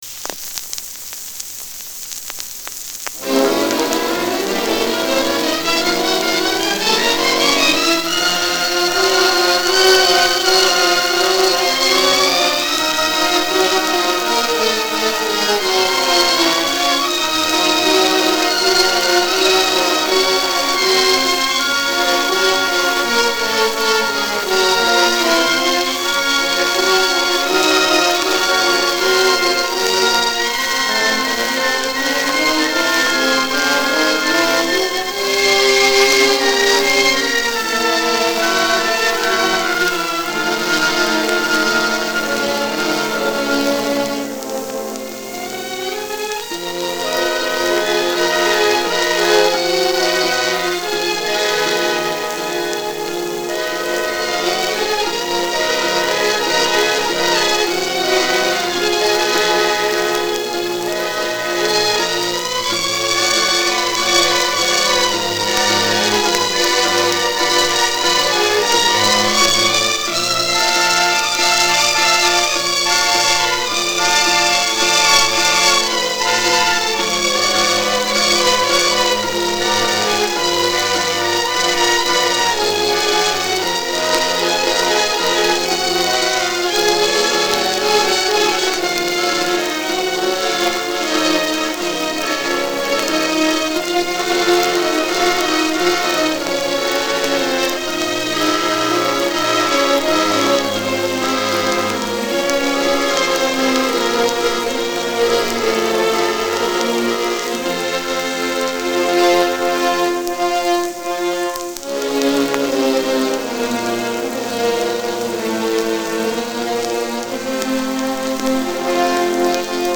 Описание: Эпическое симфоническое произведение.